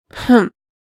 sigh.ogg.mp3